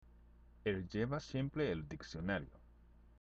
＜発音と日本語＞
（エル　ジェバ　シエンプレ　エル　ディクシオナリオ）